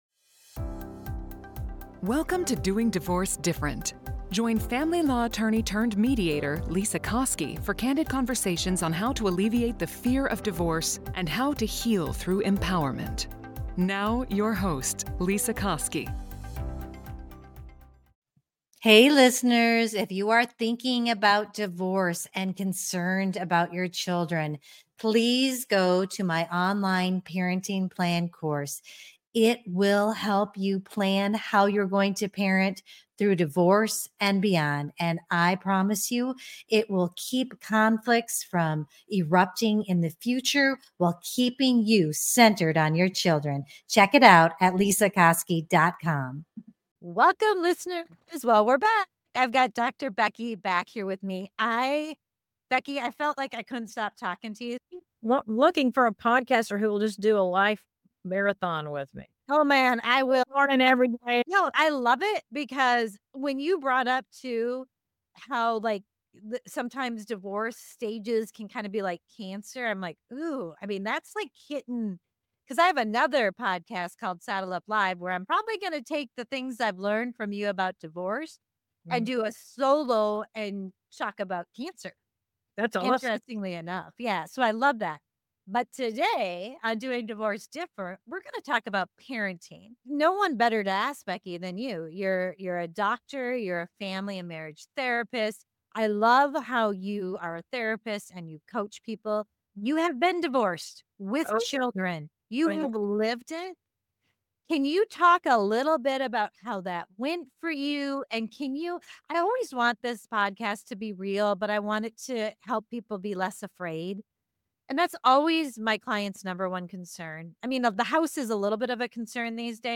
The conversation emphasizes putting children's needs first and avoiding negative influences from friends and family.